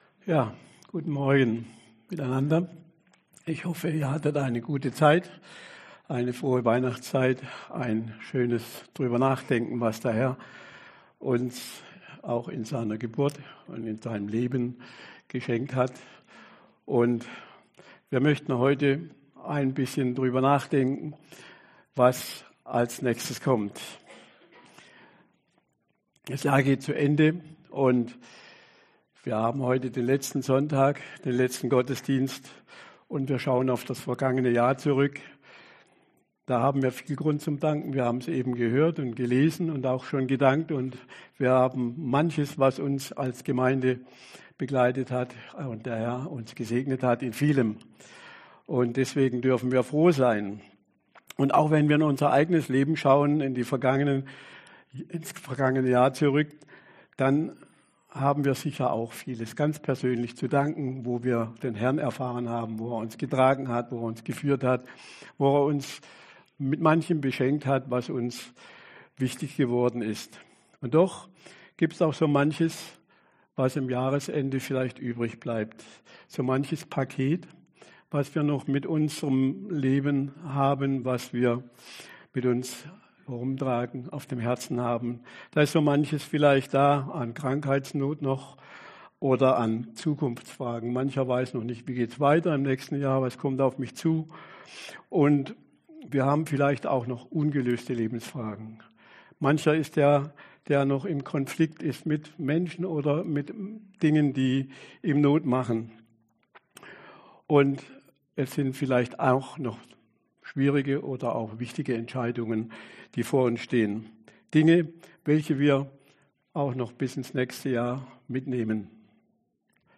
Dezember 2025 Ein frohes und friedliches Jahr Prediger